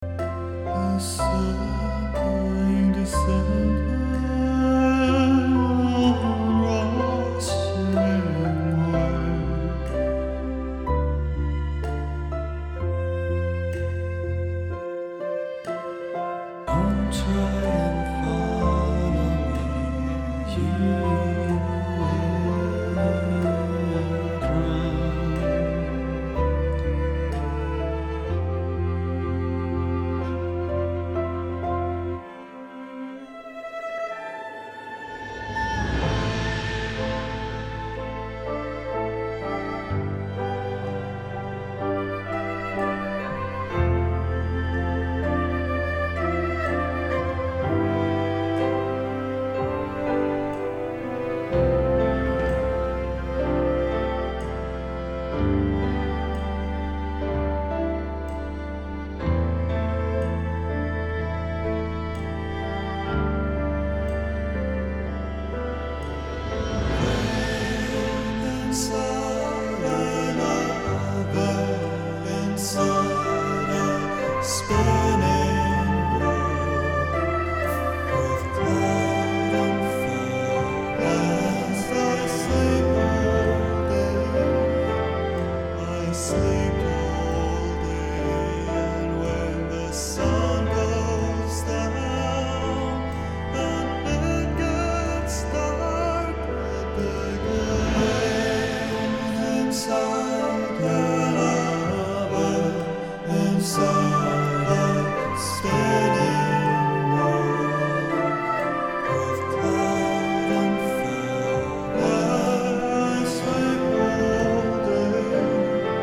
To me it sounds a bit like plastic, and i am wondering if my sound card can be the issue?
I want to state that the songs are still under proccess but you should get an idea of the sound.
What I hear is everything competing for the same sonic area - some EQing on certain tracks and judicious panning - it seems like many of the tracks are panned wide.